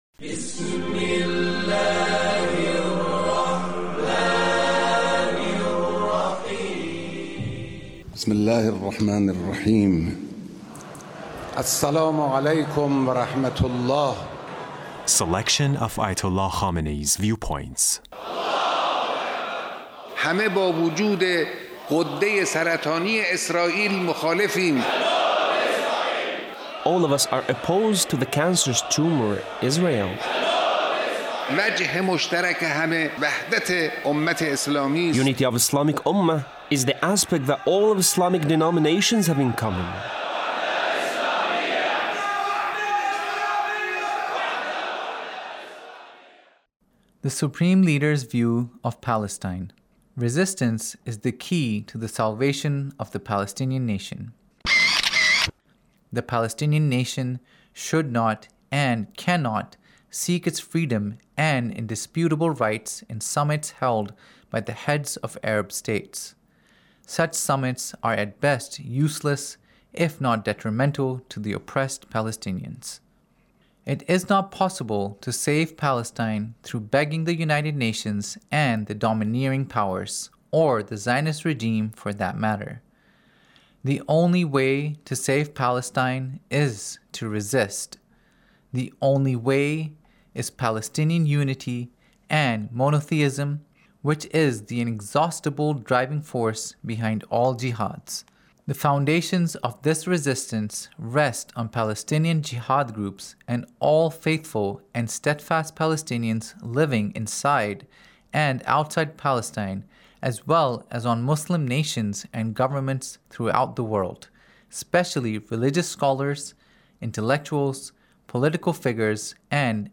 Leader's Speech (1873)